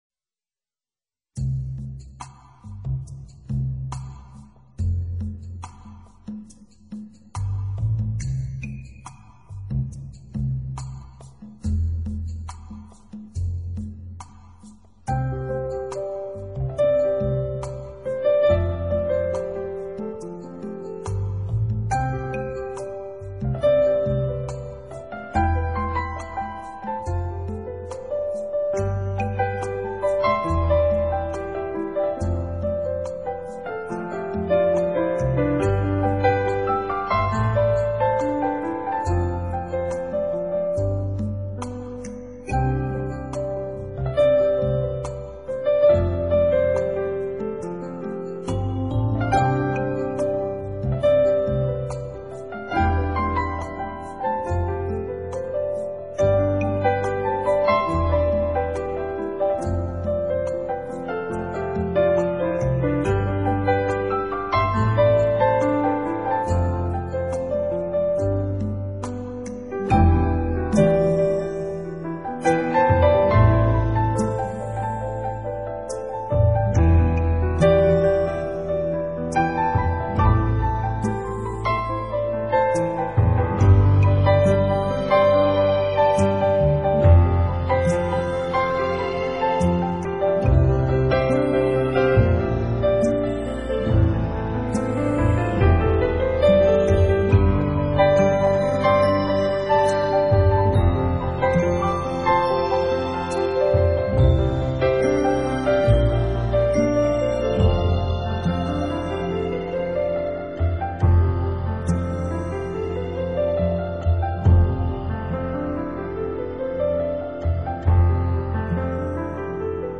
音乐类型：New Age 新世纪
由极富新意的打击节奏开场，像欣然鼓动的心跳声，意示一扇爱情新开的窗，如果你也
所以旋律安排了明显的高低起伏，蕴含着新鲜，好奇的气氛，钢琴的高音把位特别能呈
选用具有弦乐效果的合成音色，从副歌开始一路为曲子加温，烘得人